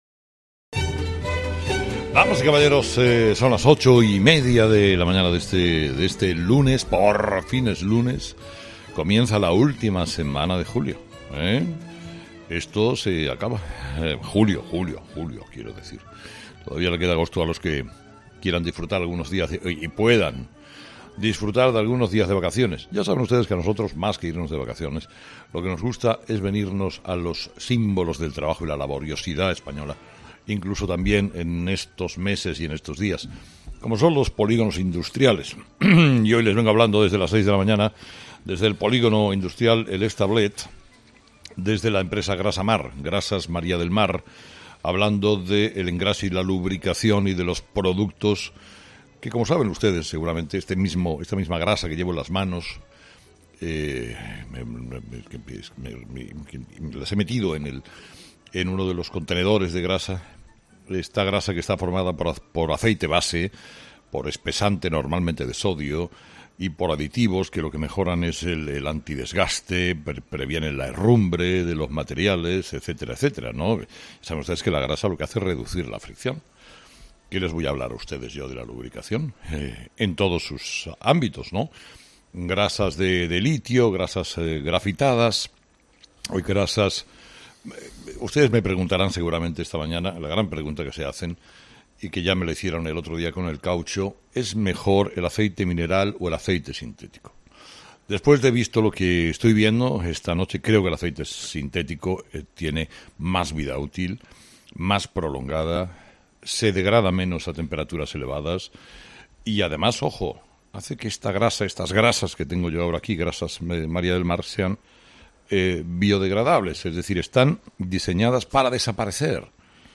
Herrera continúa la ruta de polígonos industriales de verano en una fábrica de grasa